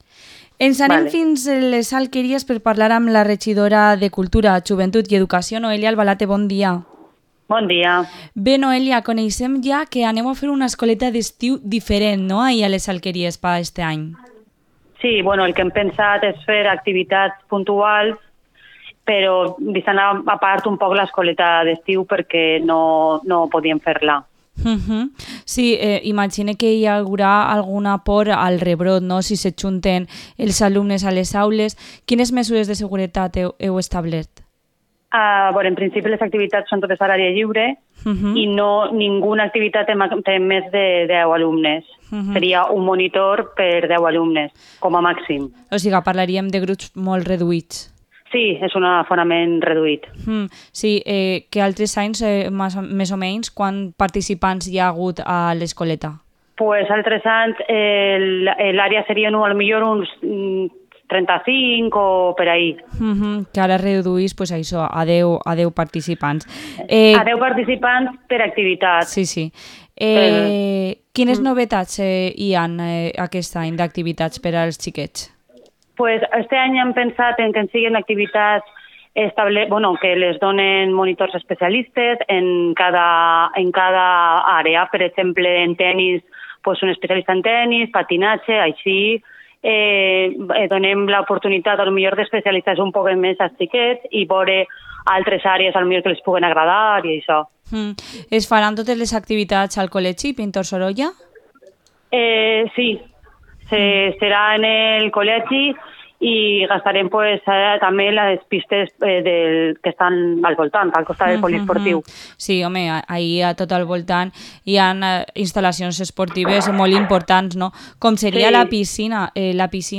Entrevista a la concejala de Cultura y Educación de Les Alqueries, Noelia Albalate